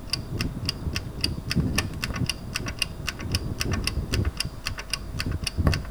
• tick tock oven clock.wav
A kitchen oven clock recorded in a large room with stone floor, using a Tascam DR 40.
tick_tock_oven_clock_2hI.wav